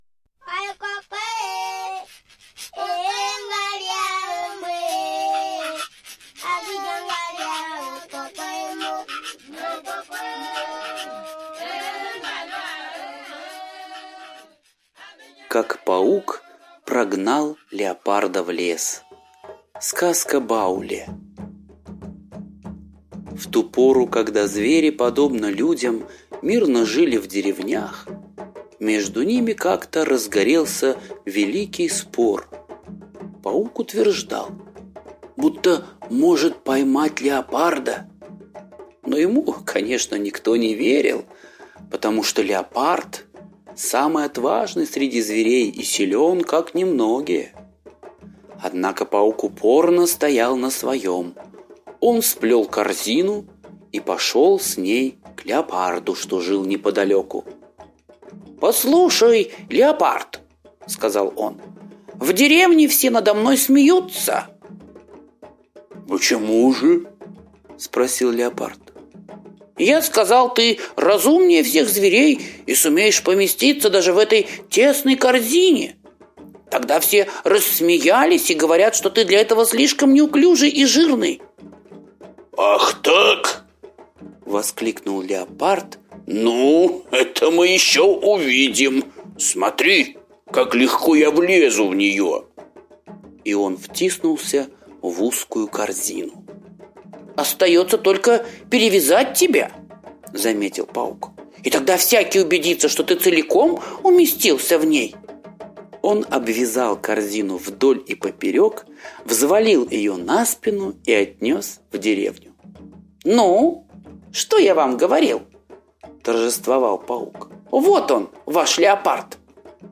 Как Паук прогнал Леопарда в лес - восточная аудиосказка - слушать онлайн | Мишкины книжки